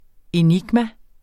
Udtale [ eˈnigma ]